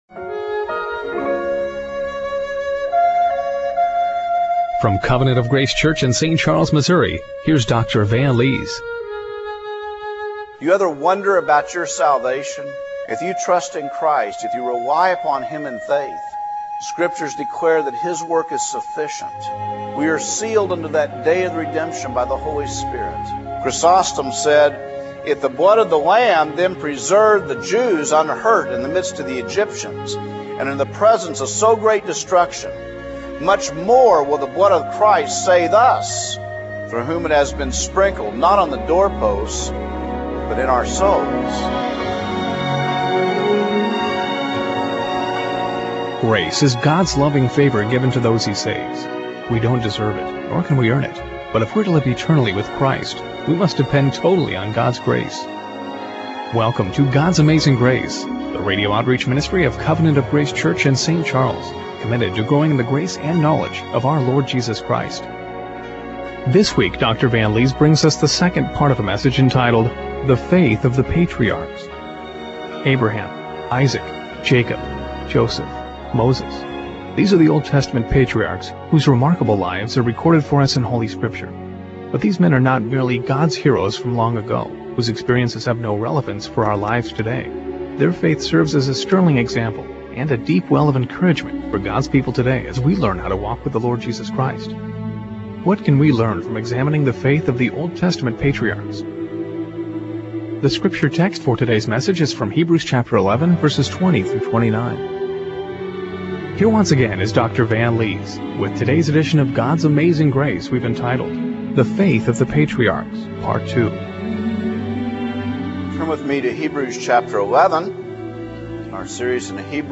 Hebrews 11:20-29 Service Type: Radio Broadcast What can we learn from examining the faith of the Old Testament patriarchs?